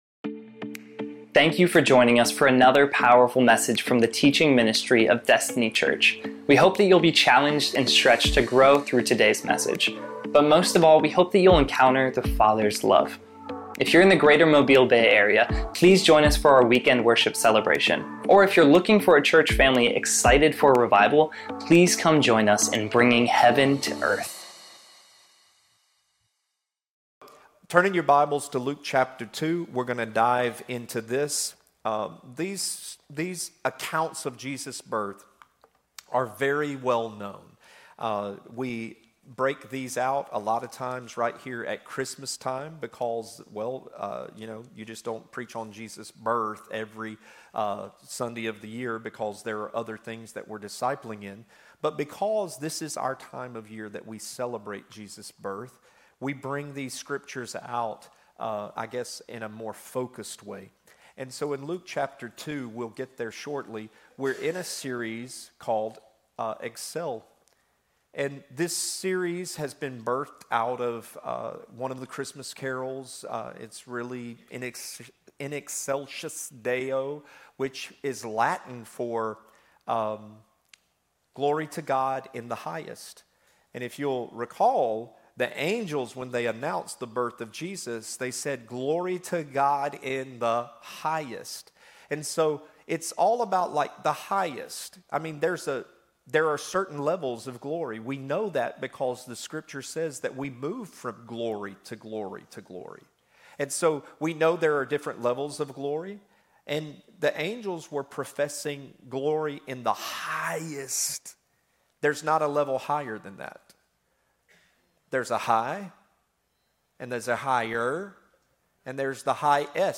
EXCEL: The MOMENTUM of giving. live-recording 12/22/2024 6:26:05 AM.